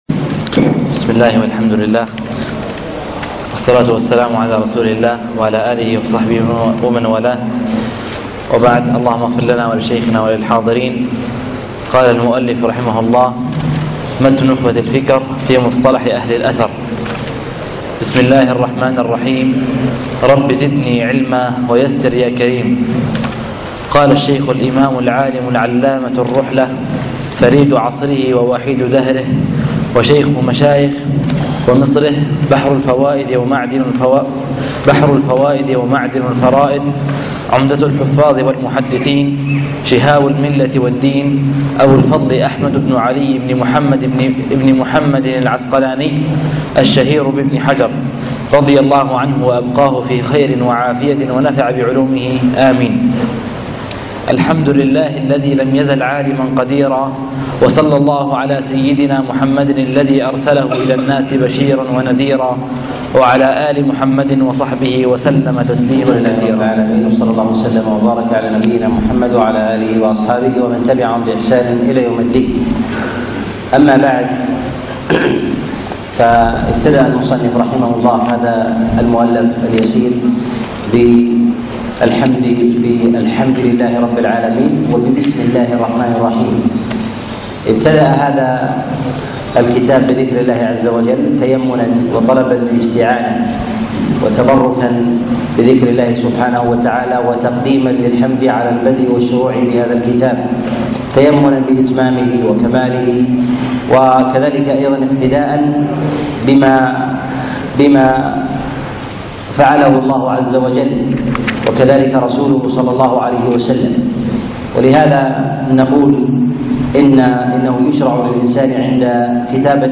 شرح نخبة الفكر الدرس 1